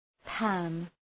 Προφορά
{pæn}